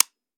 weap_romeo870_disconnector_plr_04.ogg